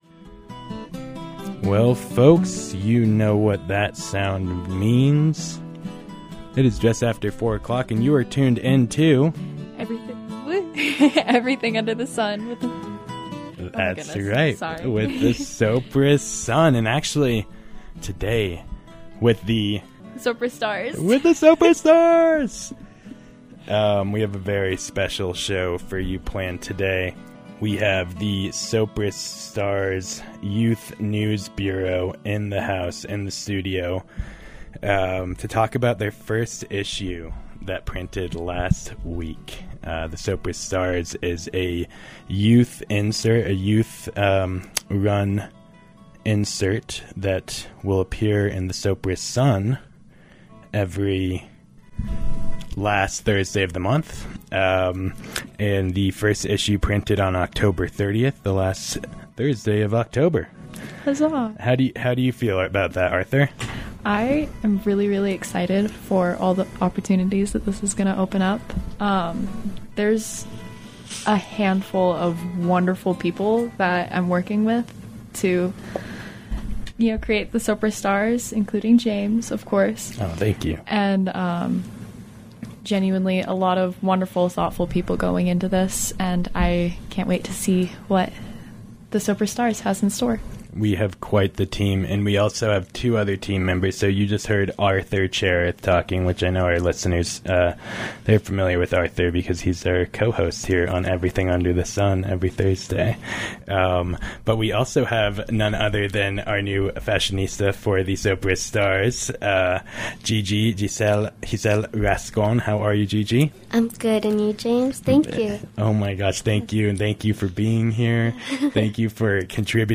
Sopris Sun staff and interns speak about the first issue of The Sopris STARS, a new monthly youth insert in the newspaper, which printed last week.